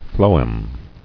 [phlo·em]